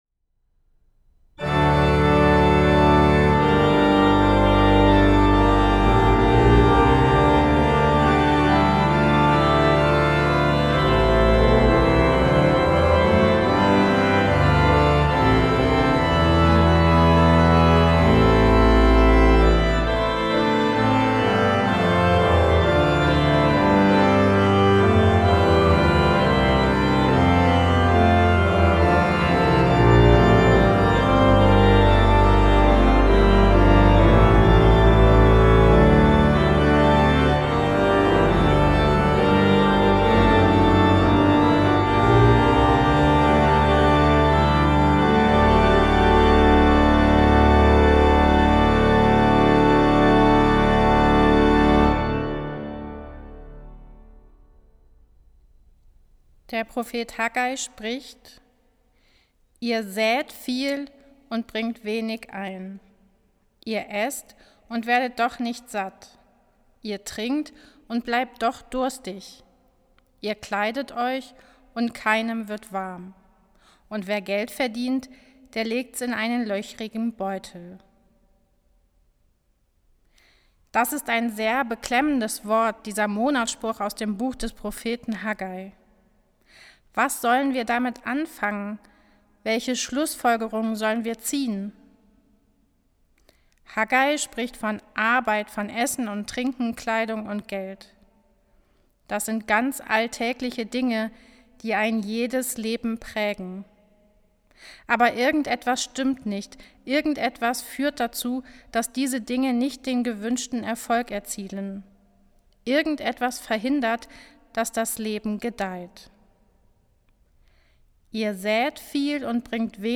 Musik: Orgelwerke von Heinrich Scheidemann (ca. 1596-1663)